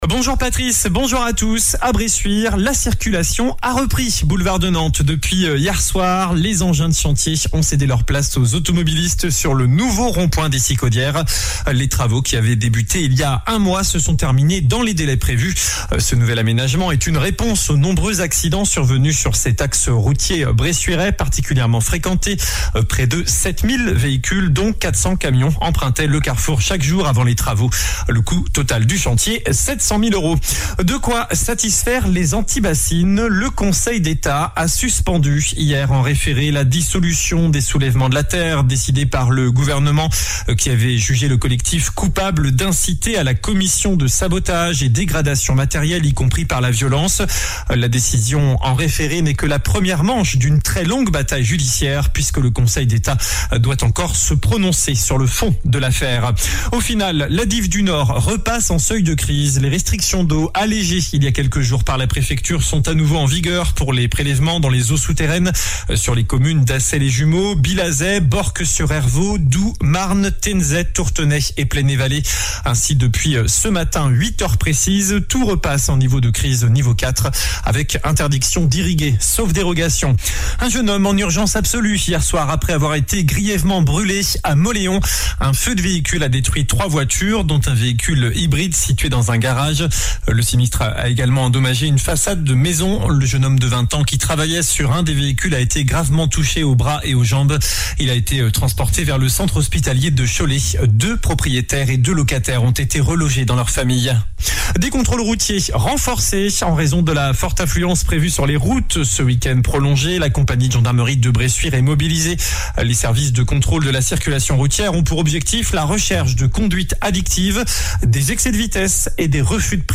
JOURNAL DU SAMEDI 12 AOÛT